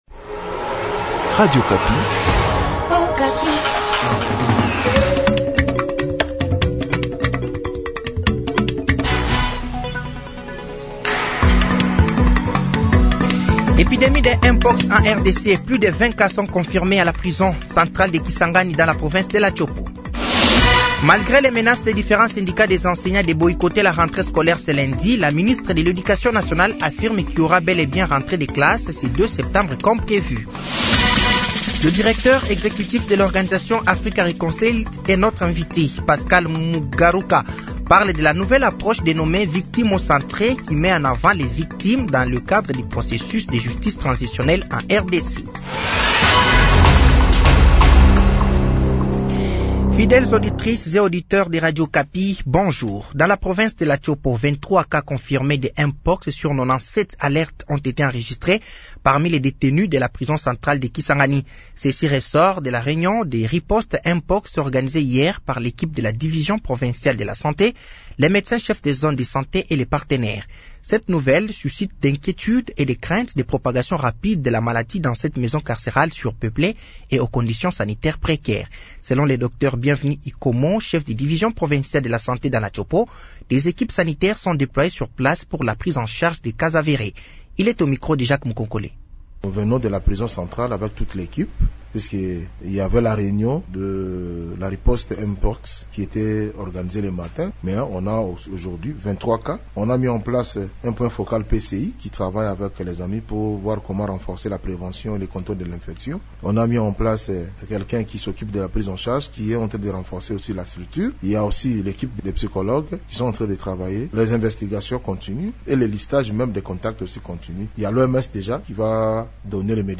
Journal français de 15h de ce samedi 31 août 2024